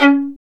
Index of /90_sSampleCDs/Roland - String Master Series/STR_Violin 2&3vb/STR_Vln3 % + dyn